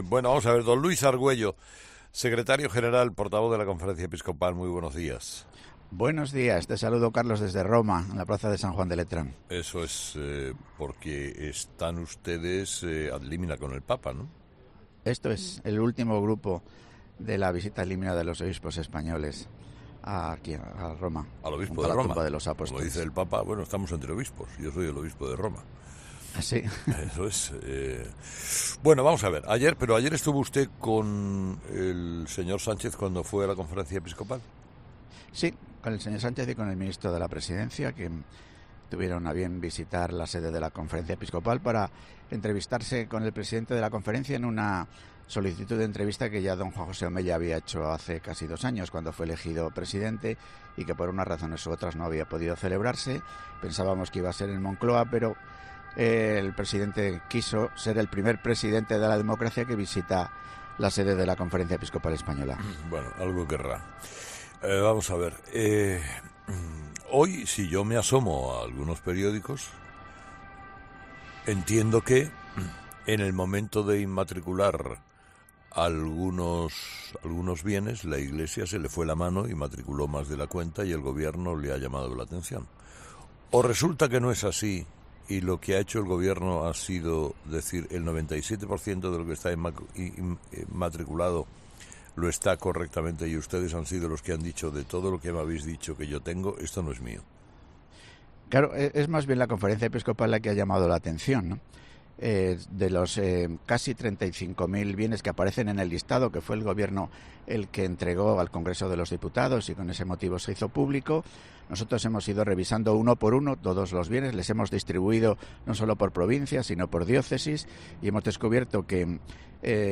El secretario general de la CEE ha analizado este martes en 'Herrera en COPE' el encuentro entre Pedro Sánchez y el card. Juan José Omella